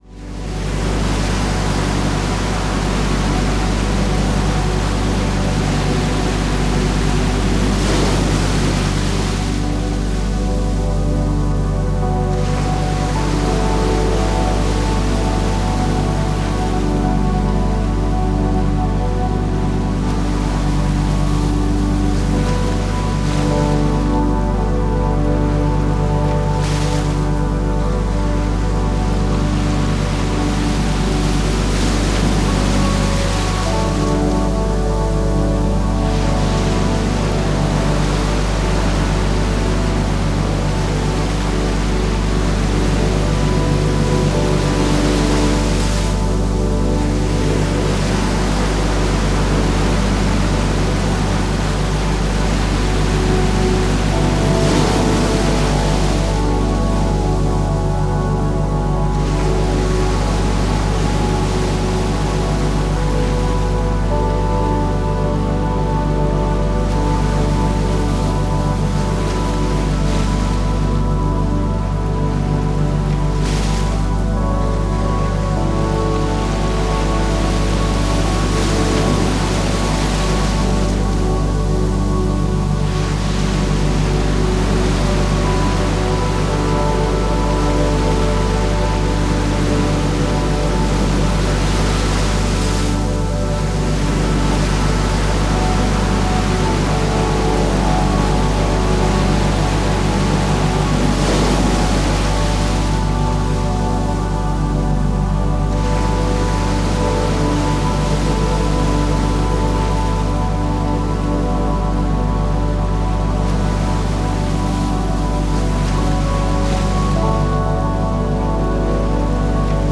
sons apaisants pour un sommeil profond - univers sonores apaisants d'une excellente qualité audio
Ce livre audio contient 20 univers sonores apaisants d'une excellente qualité audio pour créer un environnement de sommeil détendu. Les plages ont une durée de 45 à 120 minutes chacune : •bruit blanc doux et lisse, •source de montagne, •ventilateurs (2 variantes), •vagues de l'océan (2 variante...